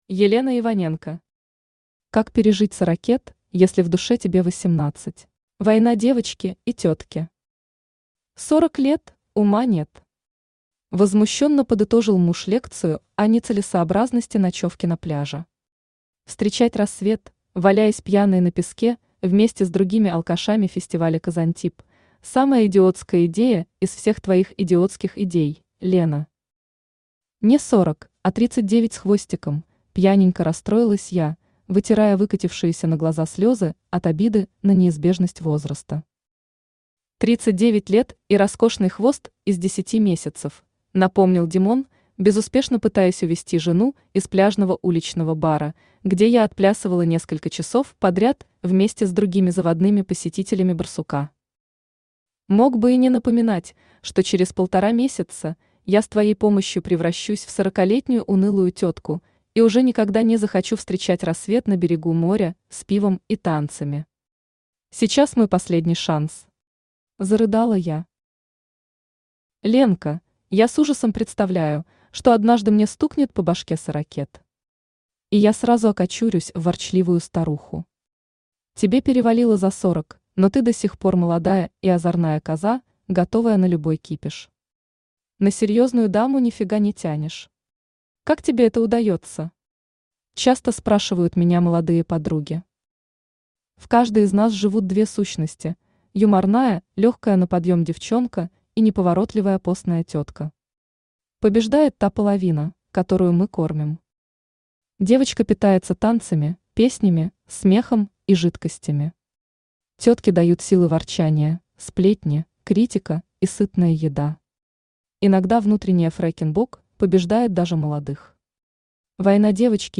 Аудиокнига Как пережить сорокет, если в душе тебе 18 | Библиотека аудиокниг
Aудиокнига Как пережить сорокет, если в душе тебе 18 Автор Елена Иваненко Читает аудиокнигу Авточтец ЛитРес.